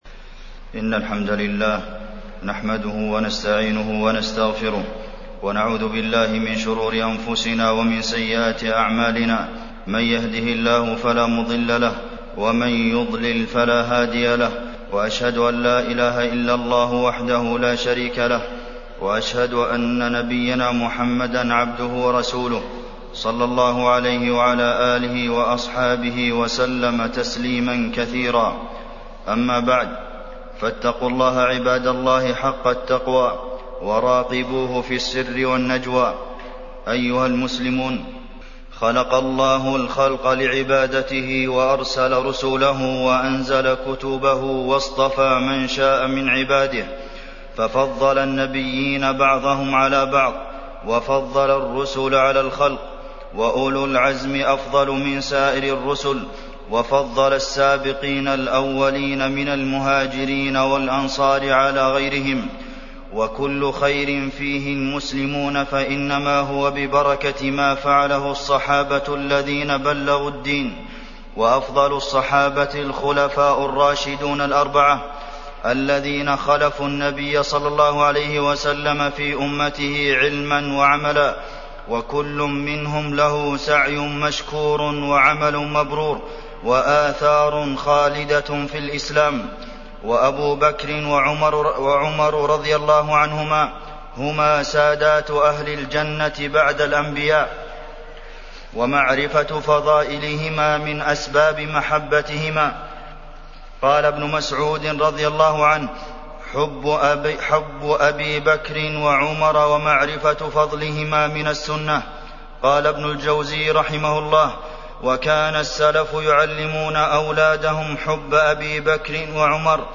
تاريخ النشر ٥ صفر ١٤٢٨ هـ المكان: المسجد النبوي الشيخ: فضيلة الشيخ د. عبدالمحسن بن محمد القاسم فضيلة الشيخ د. عبدالمحسن بن محمد القاسم من فضائل الصحابة